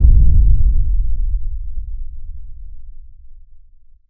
cinematic_deep_bass_rumble_01.wav